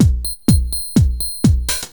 DRUMLOOP033_PROGR_125_X_SC3.wav